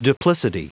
Prononciation du mot duplicity en anglais (fichier audio)
Prononciation du mot : duplicity